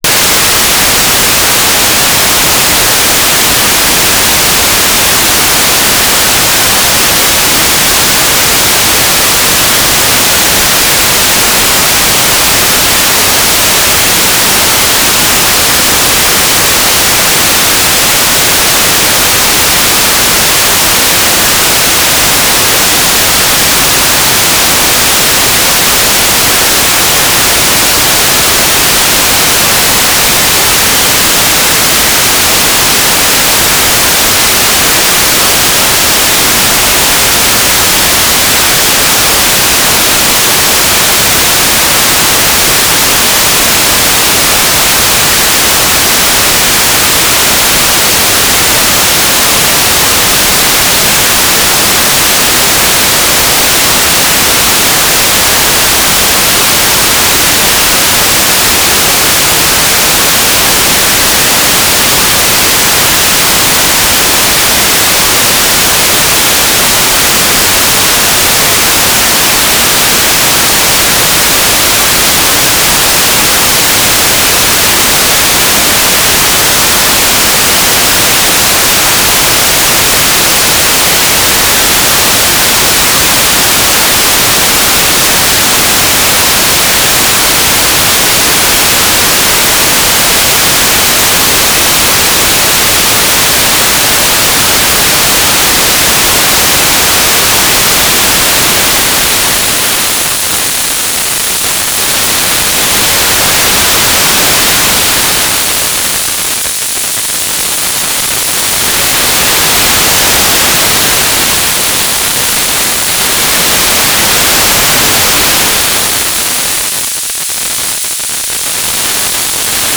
"transmitter_description": "S-band telemetry",
"transmitter_mode": "FM",